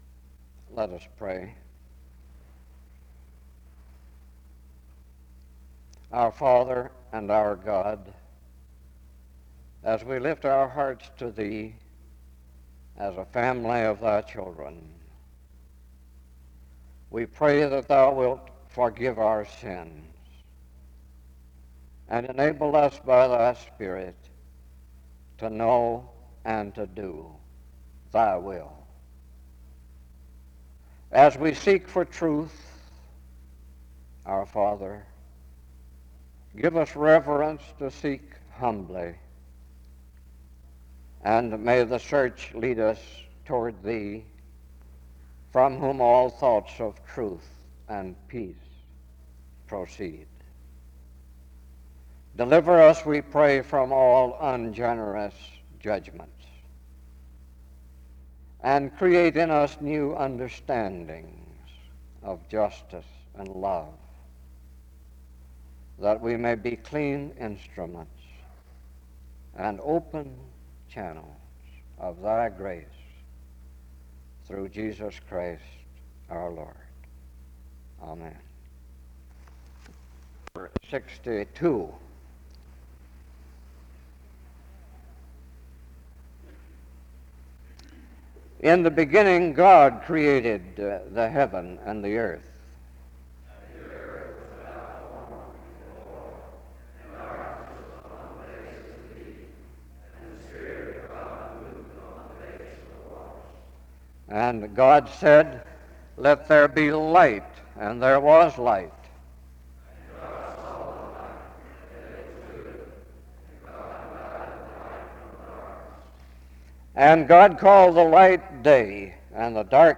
The service begins with a prayer and responsive reading (0:00-3:30).